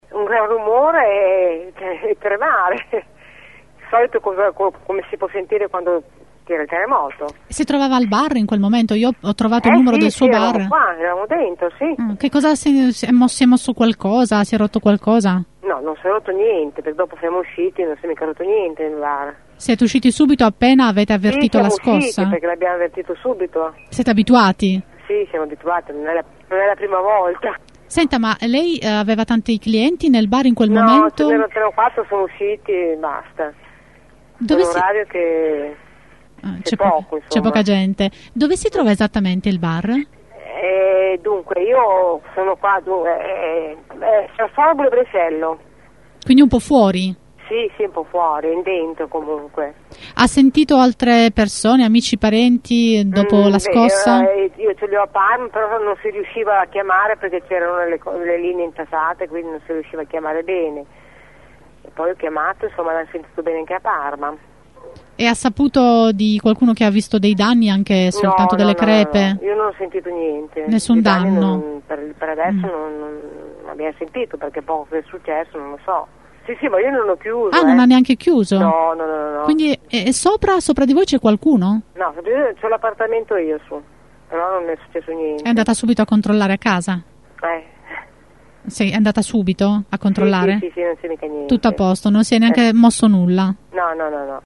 Una barista di Brescello ci racconta il momento in cui ha avvertito la scossa: